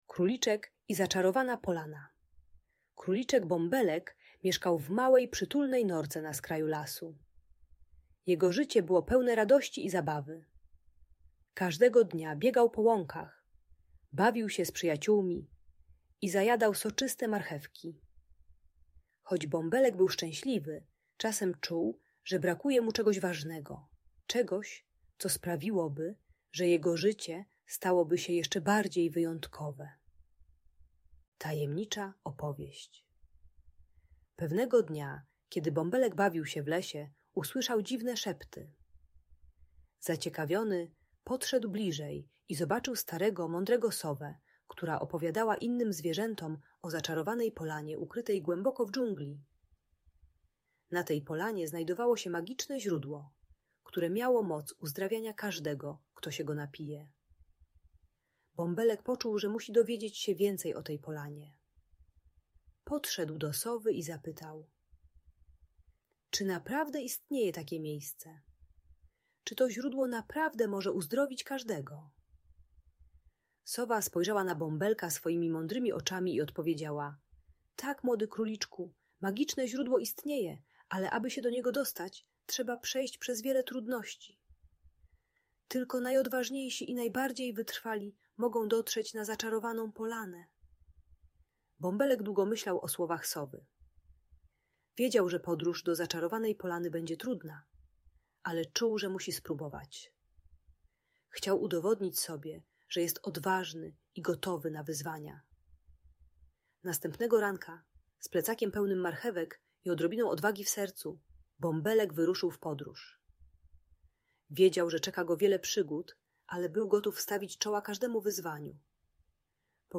Króliczek Bąbelek i Zaczarowana Polana - Audiobajka dla dzieci